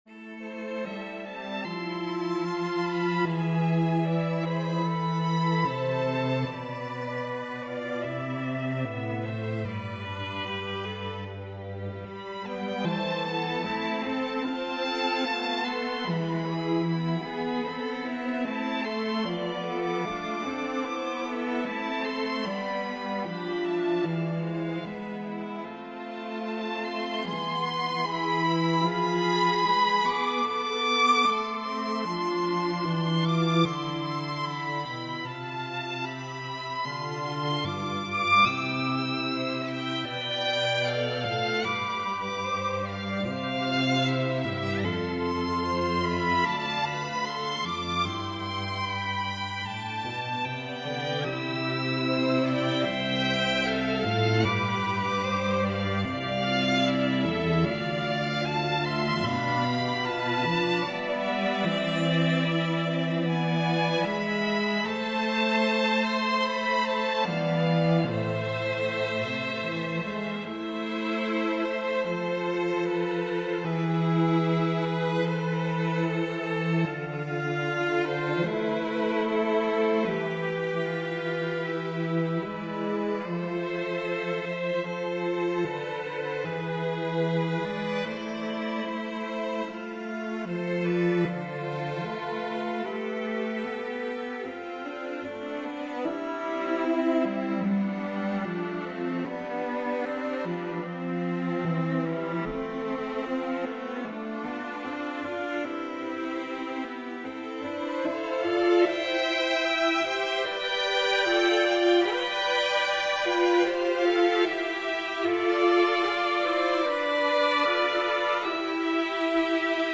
Folk/Traditional, Worship/Spiritual